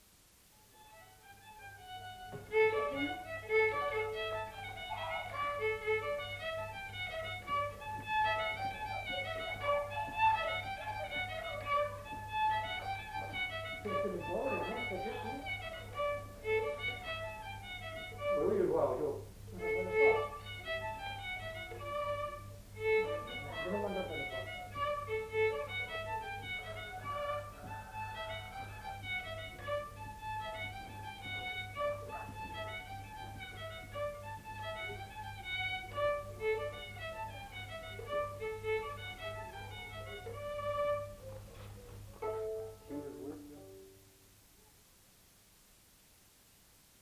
Aire culturelle : Lomagne
Genre : morceau instrumental
Instrument de musique : violon
Danse : rondeau
Ecouter-voir : archives sonores en ligne